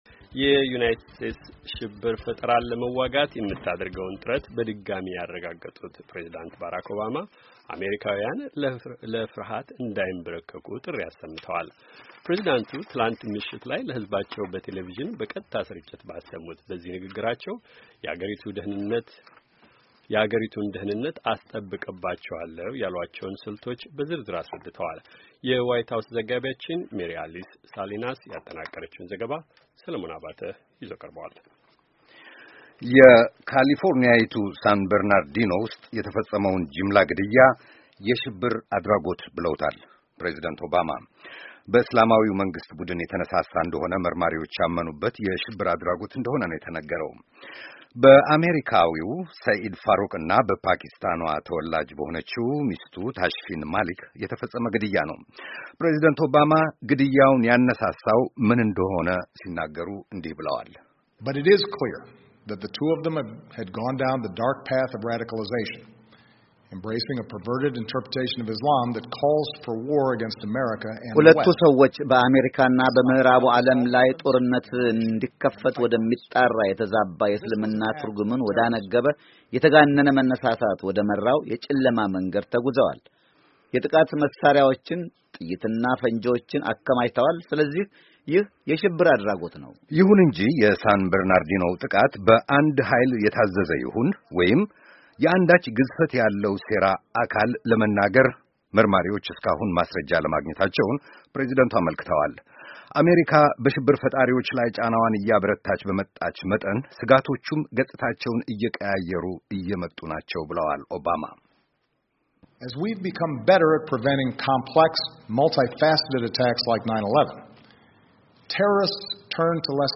ዘገባውን